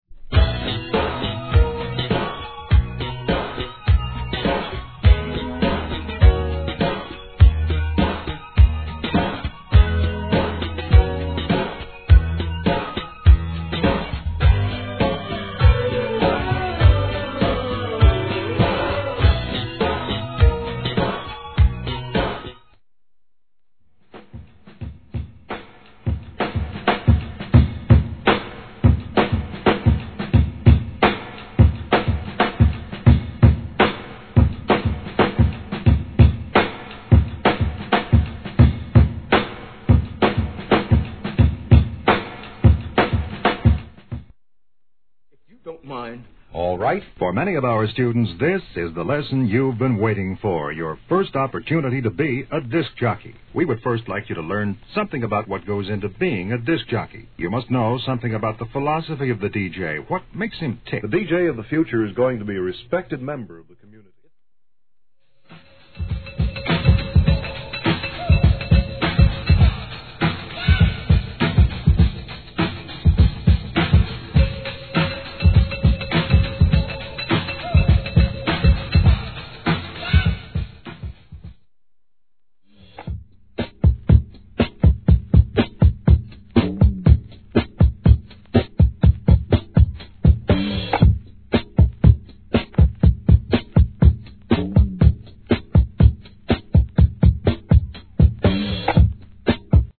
HIP HOP/R&B
ビート・メイカーへお勧めのブレイク・ビーツ!